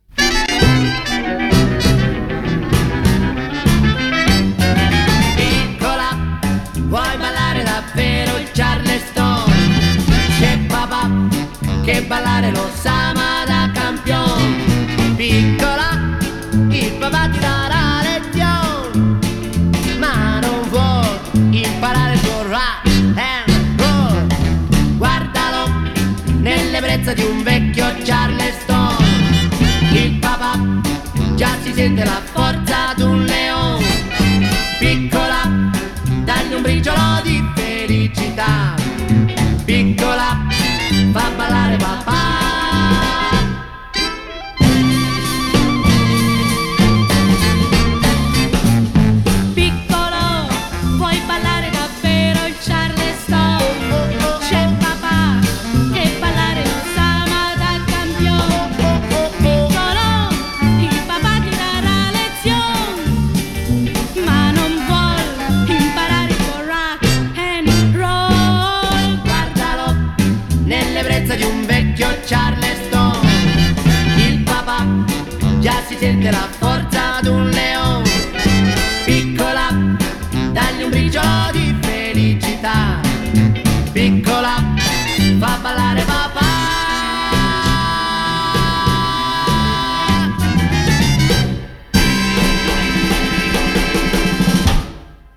Genre: Pop, Jazz, Soundtrack, Instrumental, Divers, Italian